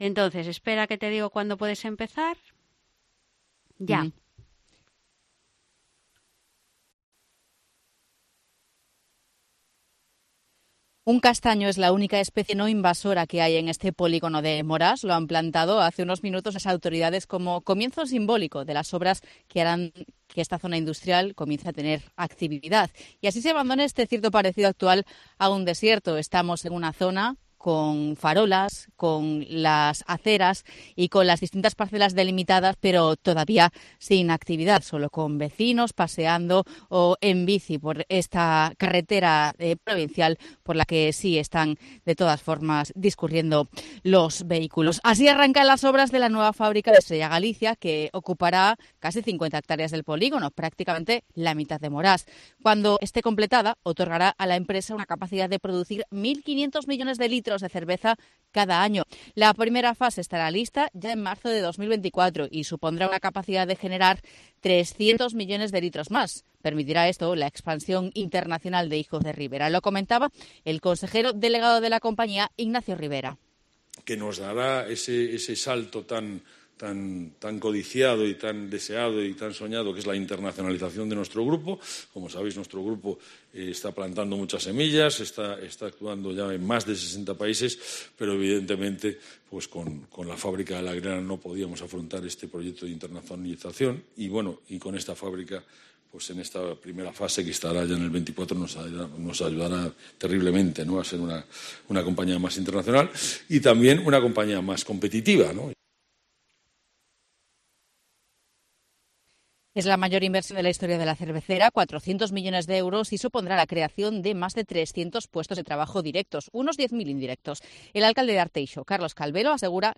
Crónica de la inauguración de las obras de la fábrica Estrella Galicia en Morás (Arteixo)